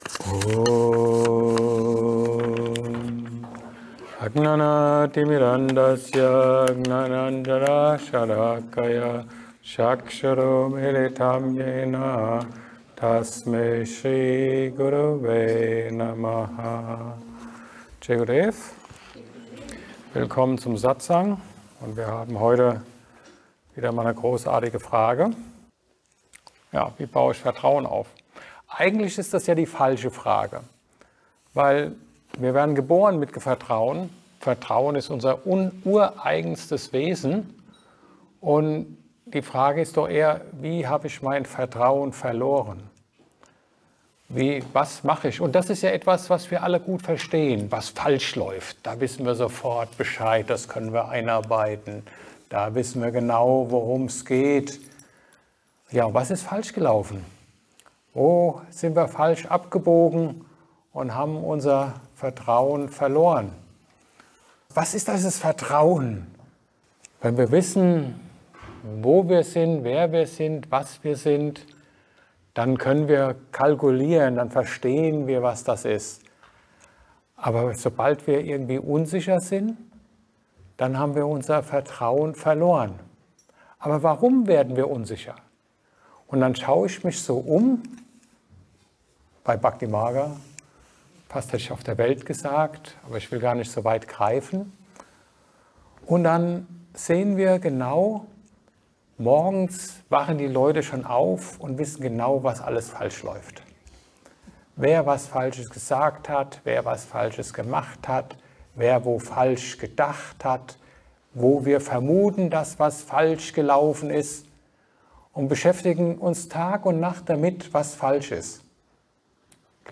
Ein Satsang